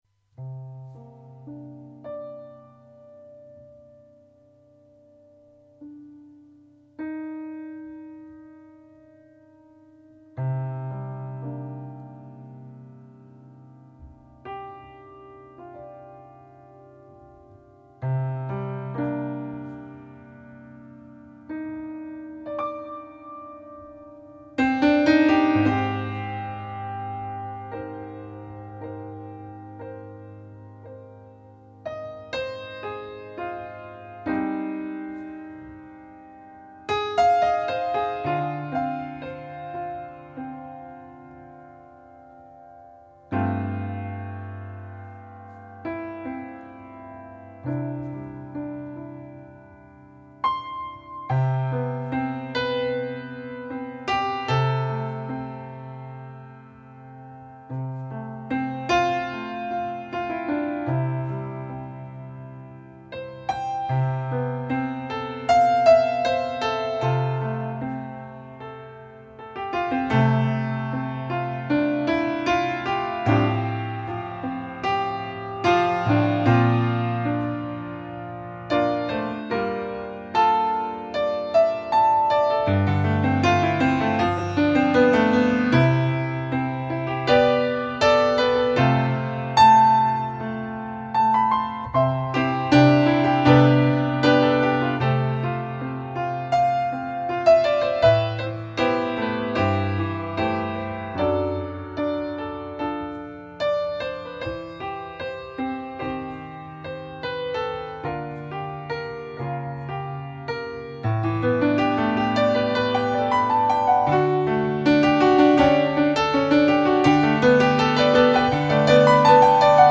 Guten Morgen (Improvisation)